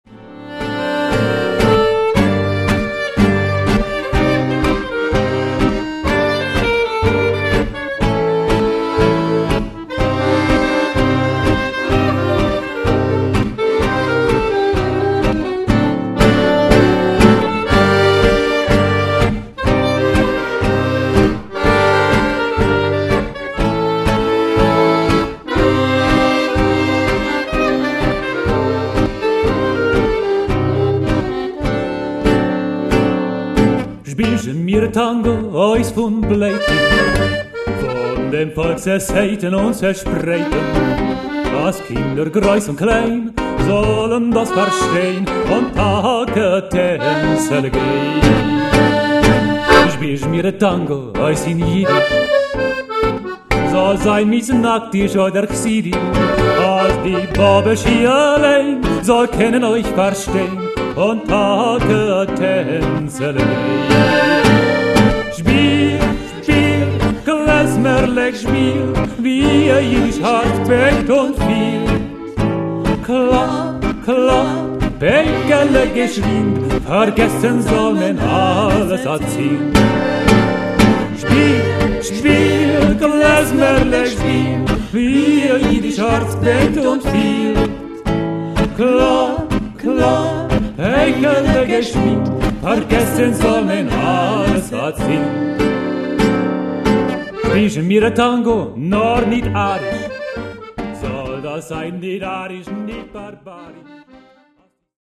es gibt mehrere leichte variationen in melodie und text; besonders die schlußzeile "jetzt soll man alles vergessen" heißt in anderen fassungen "spielt mit seele und gefühl..."